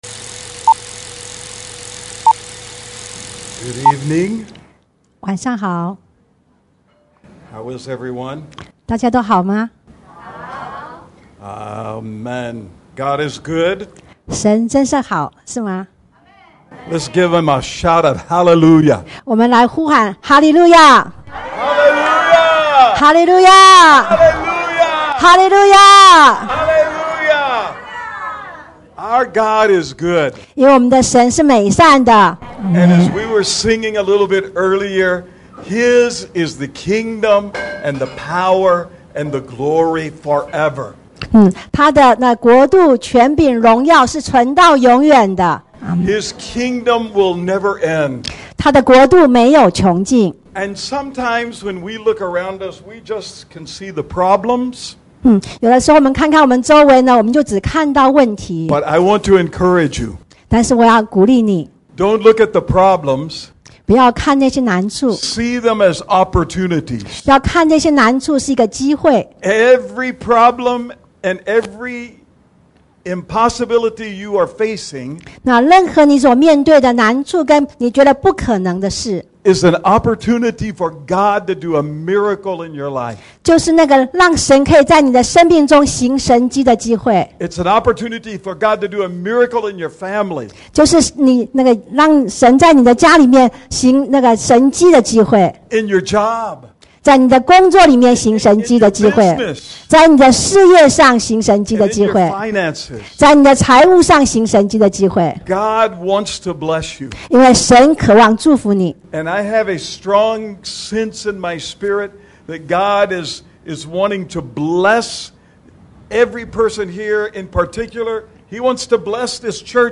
地點：高雄CPE領袖學院